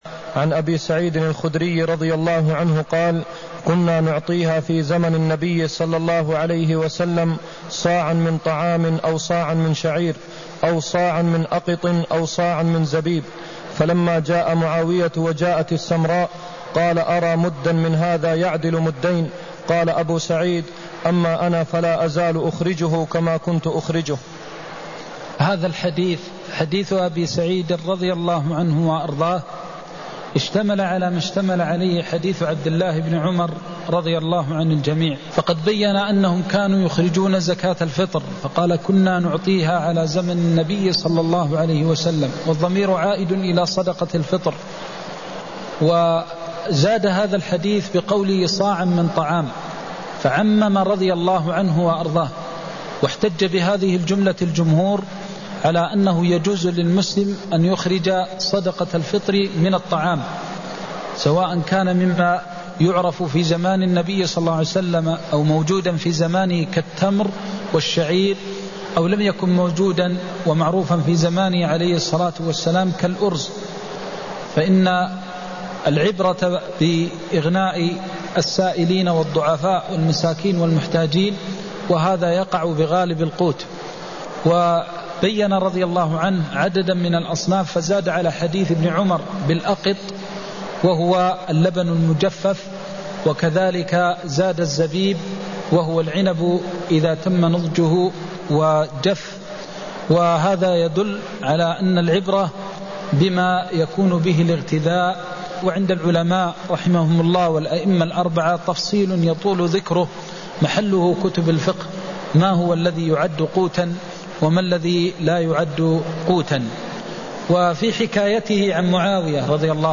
المكان: المسجد النبوي الشيخ: فضيلة الشيخ د. محمد بن محمد المختار فضيلة الشيخ د. محمد بن محمد المختار مقدار زكاة الفطر (169)